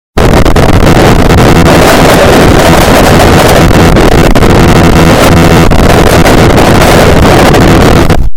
Freaky Scream Sound Button: Unblocked Meme Soundboard